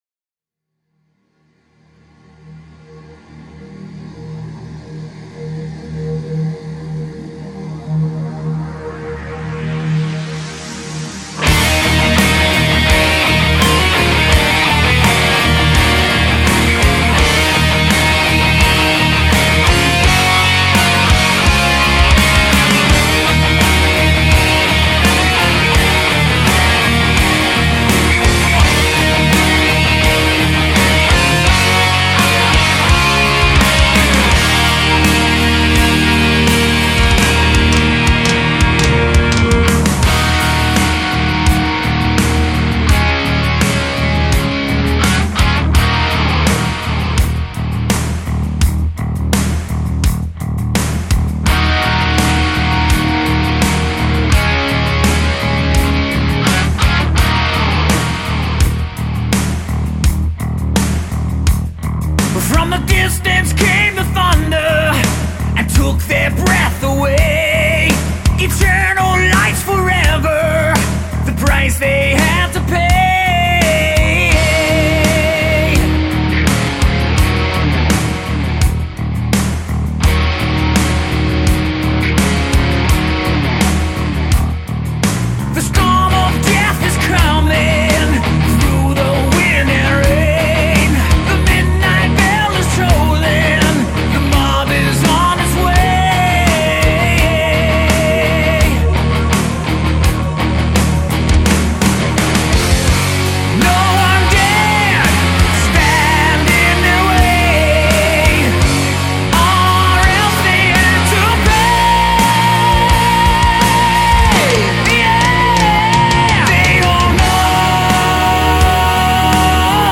Жанр: classicmetal